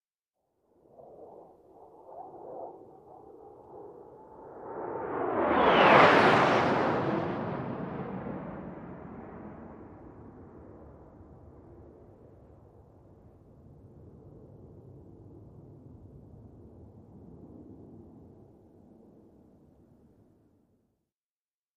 F-16 Fighting Falcon
F-16 Fly By Very Fast